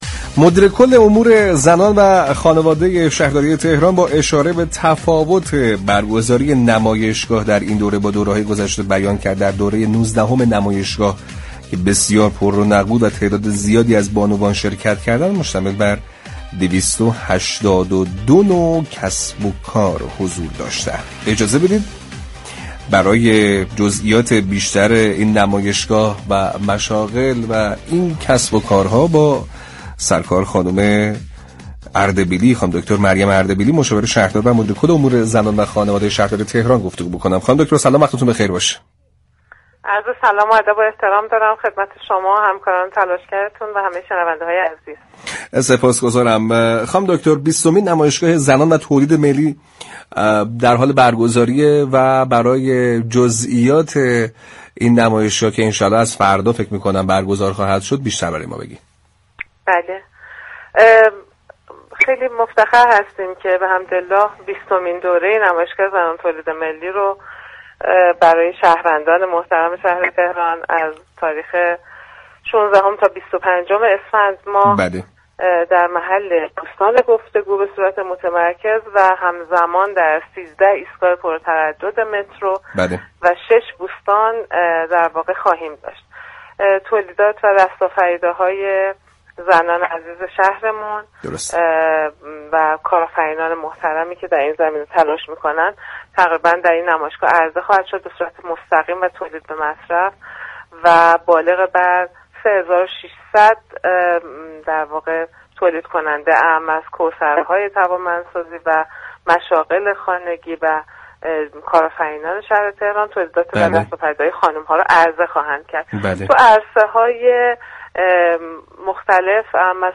در گفتگو با برنامه «سعادت آباد»